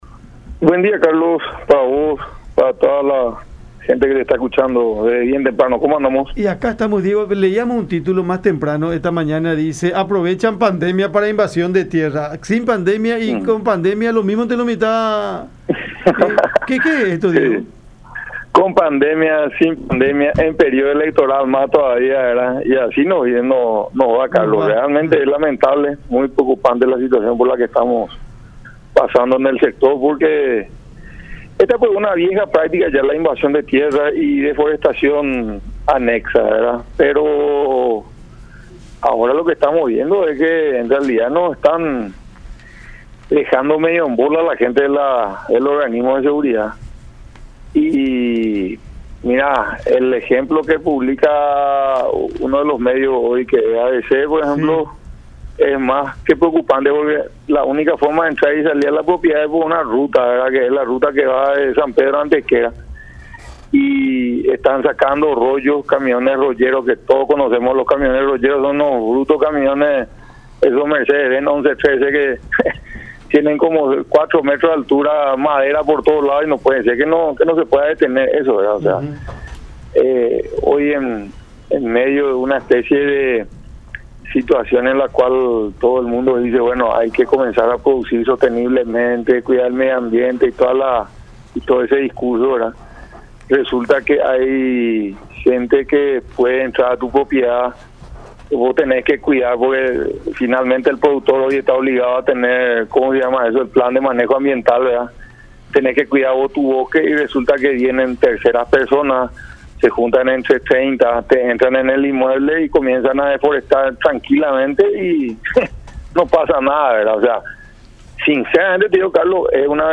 en contacto con Cada Mañana por La Unión